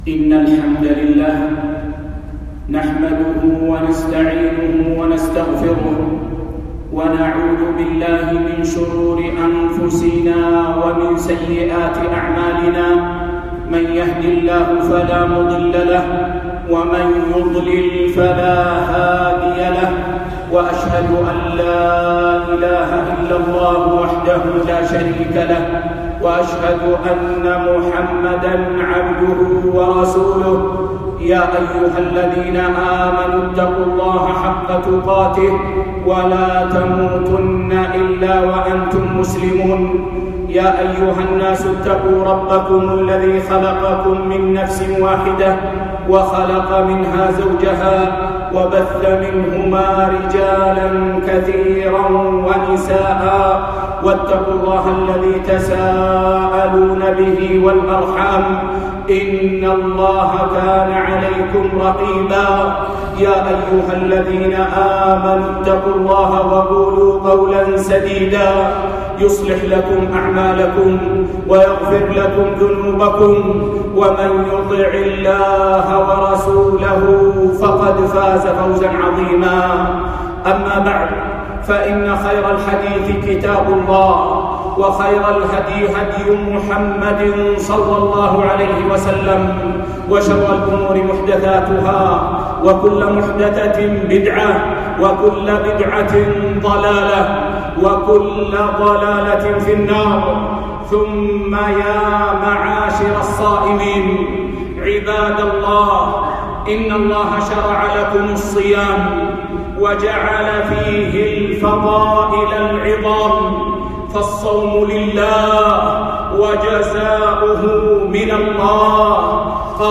خطبة بعنوان